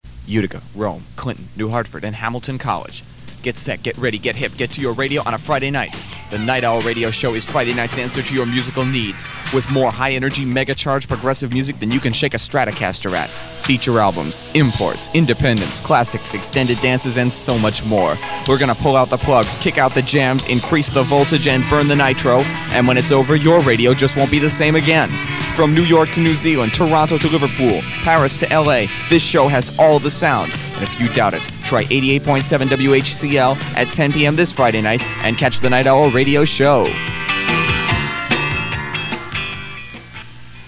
The sound quality is poor, and I don’t remember if I recorded them just to hear how I sounded back in the day, or if I thought by putting them together I could create an audio resume for a future radio job.
Somehow, in this pile of old cassettes, I actually found an old clip of an on-air promo for “The Nightowl Radio Show,” my Friday night block of imported music and alternative “college rock” tracks.